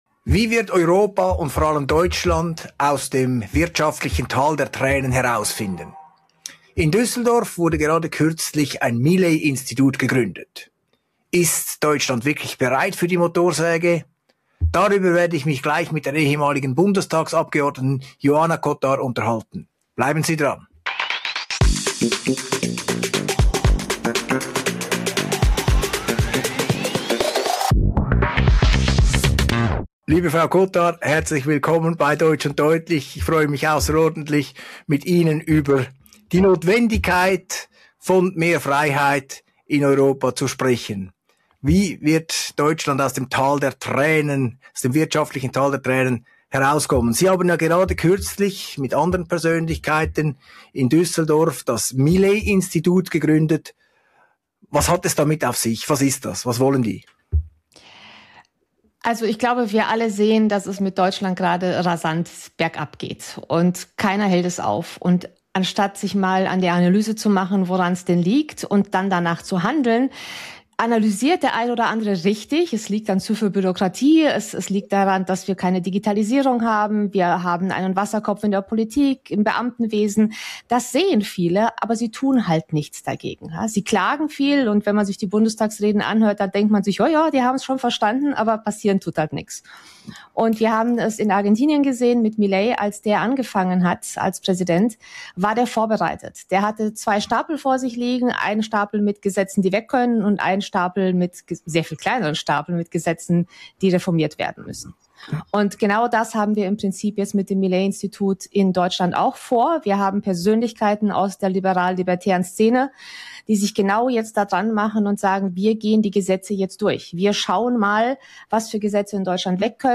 Im Interview für «Deutsch und deutlich» mit Claudio Zanetti erläutert Joana Cotar die Ziele des neu gegründeten Millei-Instituts mit Sitz in Düsseldorf.